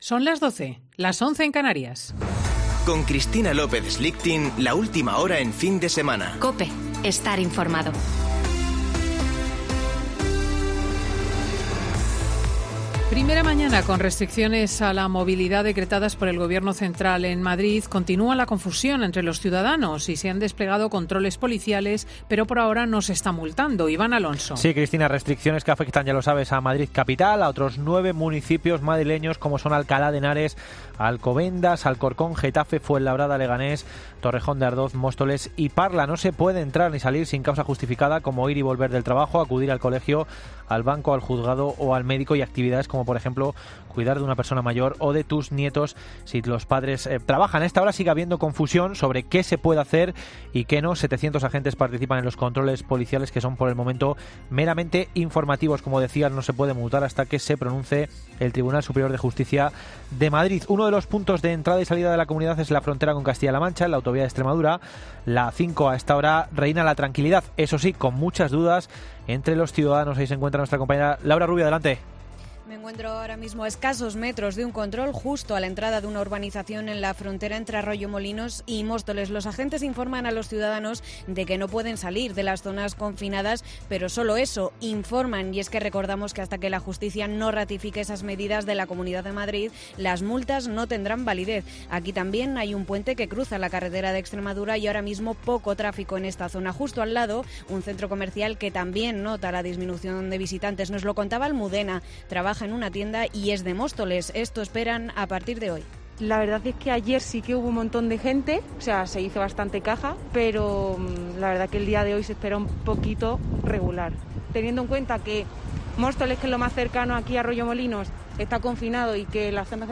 Boletín de noticias de COPE del 3 de Octubre de 2020 a las 12.00 horas